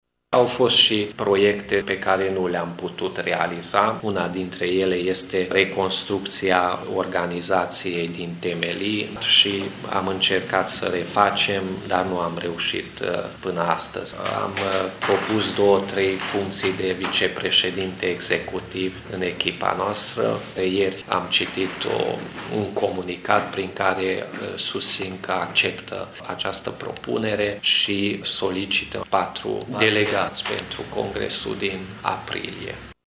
într-o conferinţă de presă